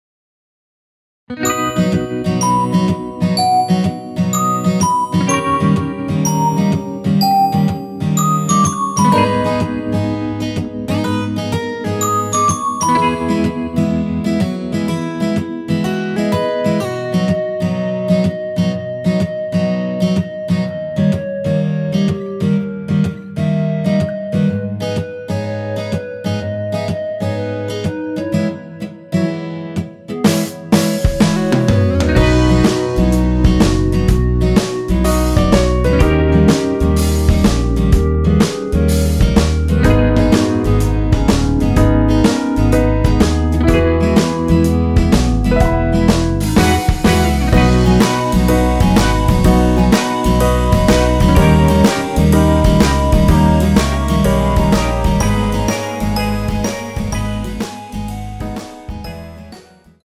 원키에서(-1)내린 멜로디 포함된 MR입니다.
멜로디 MR이라고 합니다.
앞부분30초, 뒷부분30초씩 편집해서 올려 드리고 있습니다.
중간에 음이 끈어지고 다시 나오는 이유는
위처럼 미리듣기를 만들어서 그렇습니다.